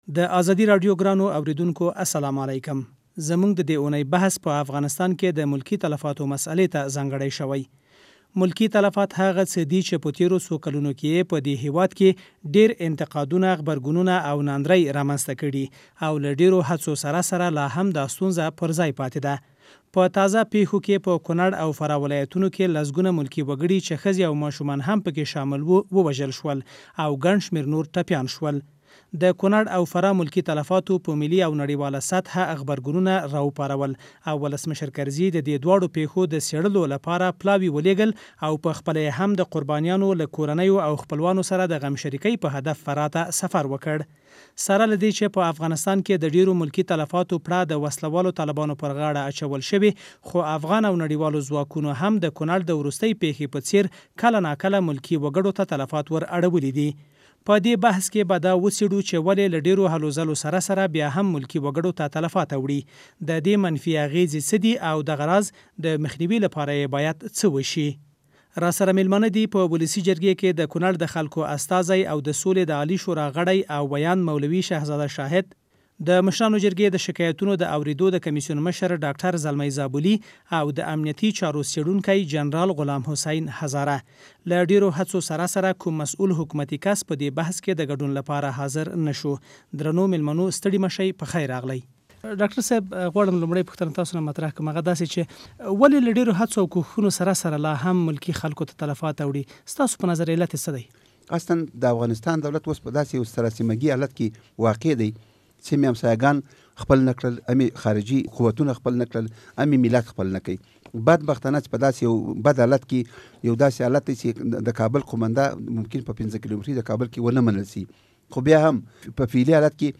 د ازادۍ راډيو د دې اوونۍ په بحث کې په افغانستان کې د ملکي تلفاتو پر مسئله خبرې شوې دي.